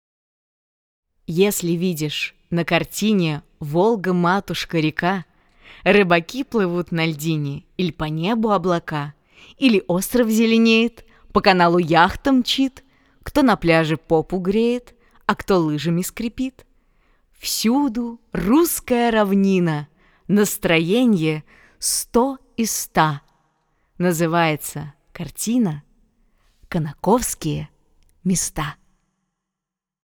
Из ниоткуда врывающийся мужской голос деревенского жителя